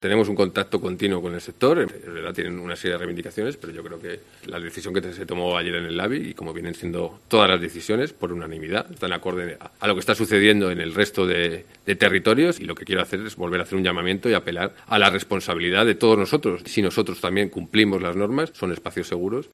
Javier Hurtado, consejero de Turismo, Comercio y Consumo